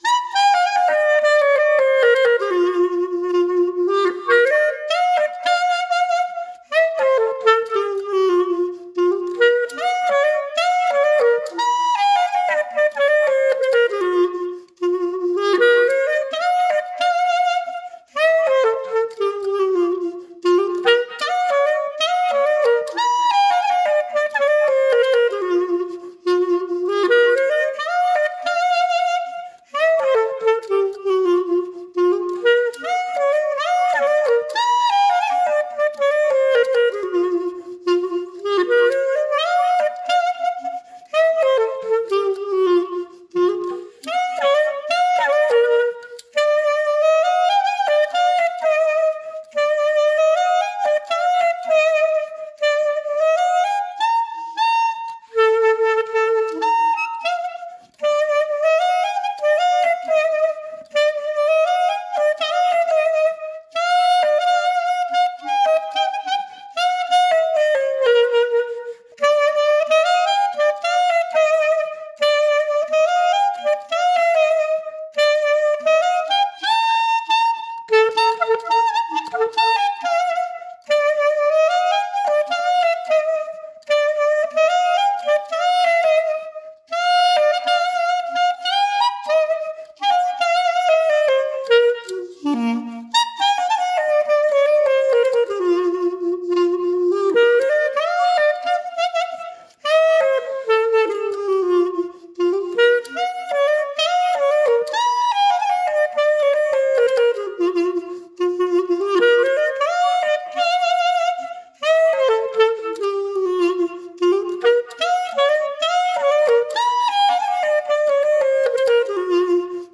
Was schließlich den Klang betrifft, so ist mir persönlich kein Unterschied zum Klang der Traditionsklarinette aufgefallen - abgesehen von einer größeren Lautstärke besonders im oberen Chalumeau-Bereich.